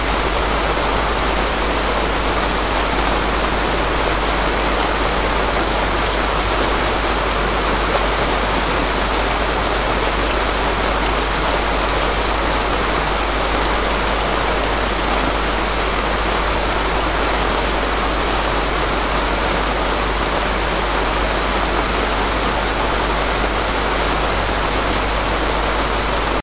rain-1.ogg